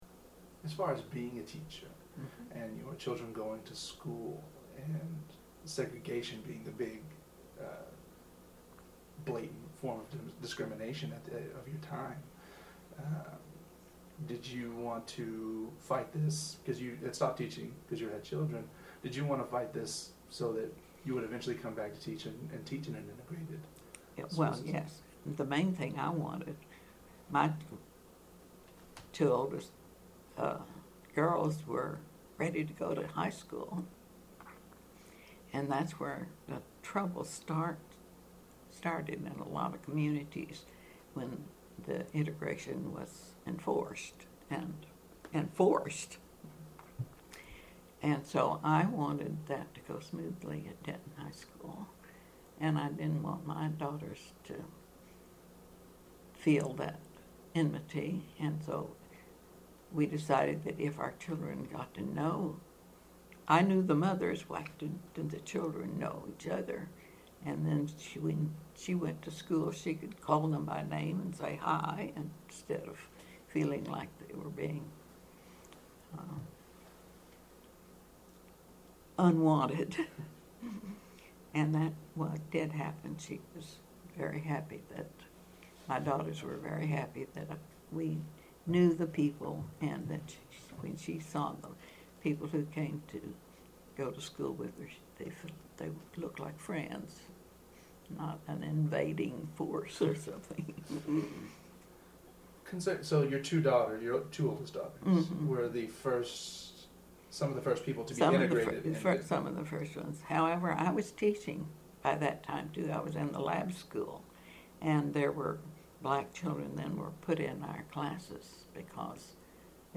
Excerpt of an Oral History Interview